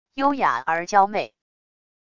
优雅而娇媚wav音频